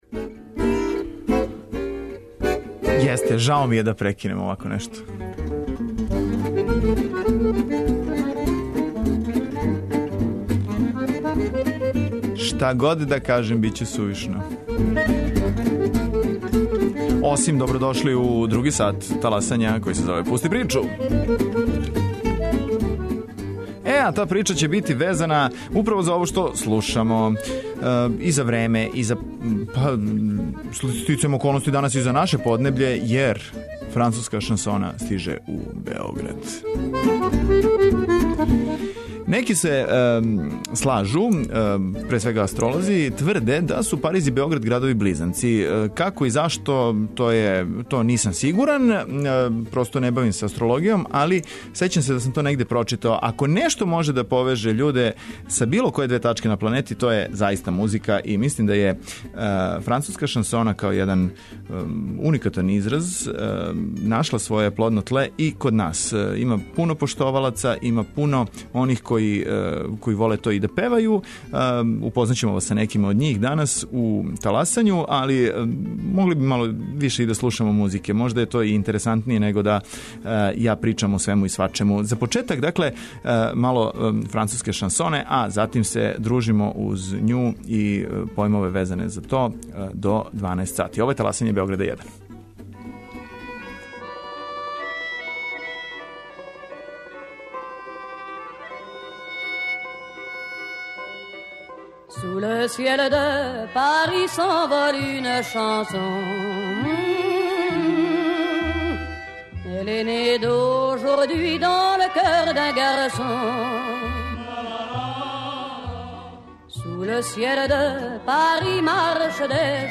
Пролеће и лето у Београду састаће се уз звуке француске шансоне.
Њихове песме слушаћемо на Уличном фестивалу француске шансоне, који ће бити одржан у Београду. Шансоне ће на Светски дан музике, али и у нашој емисији, певати победници конкурса француске музике.